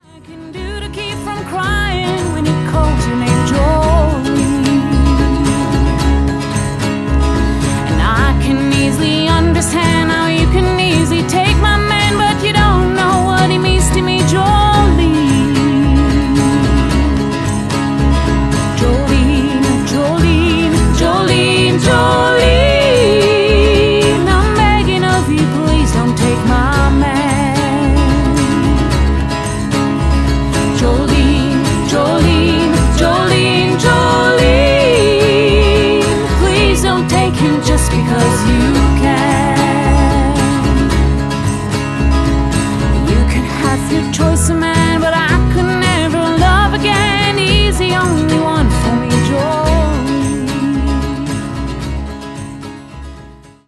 Enregistrement et Mixage